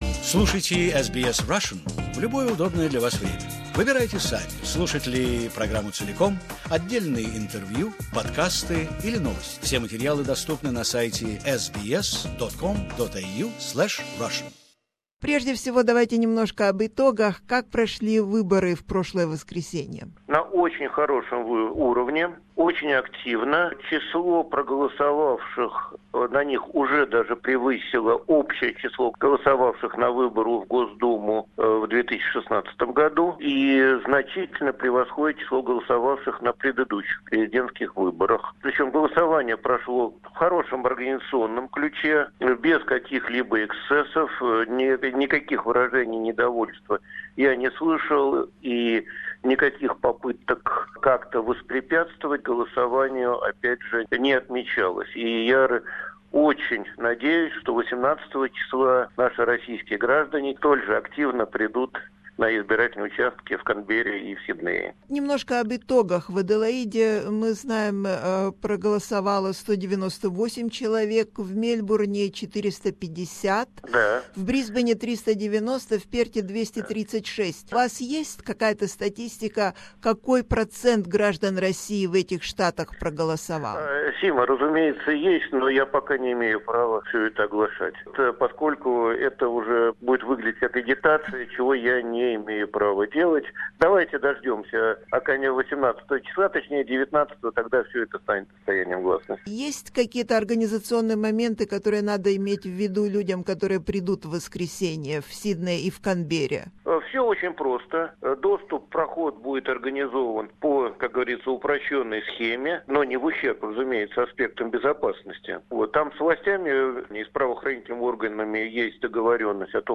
С Послом Российской Федерации в Австралии Григорием Логвиновым мы обсуждаем подготовку к Президентским выборам 18-го марта в Сиднее и Канберре. Г-н Логвинов доволен явкой избирателей для досрочных выборов в других штатах, которая оказалась выше, чем на прошлых выборах.